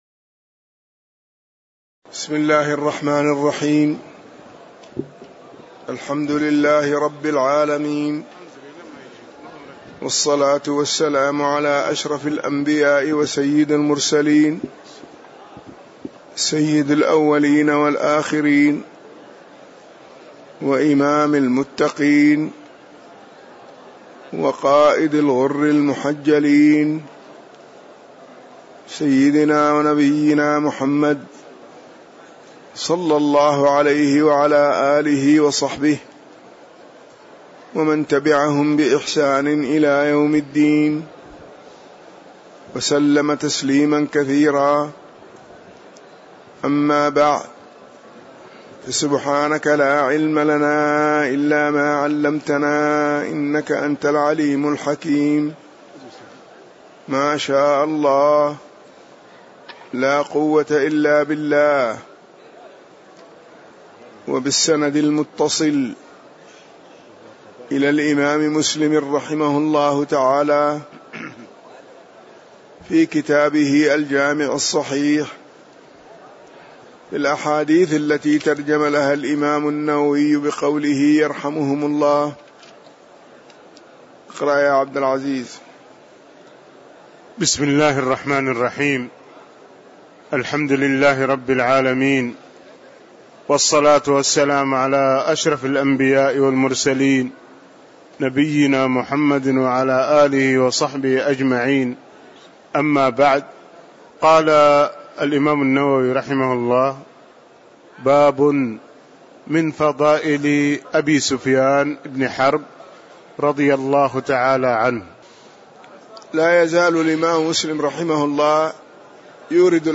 تاريخ النشر ٢٩ شوال ١٤٣٧ هـ المكان: المسجد النبوي الشيخ